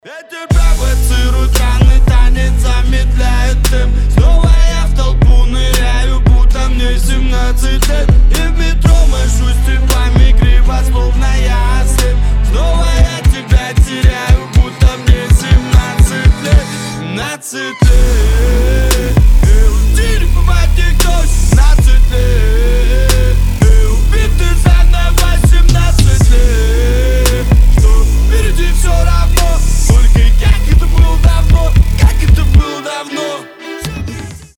гитара
Хип-хоп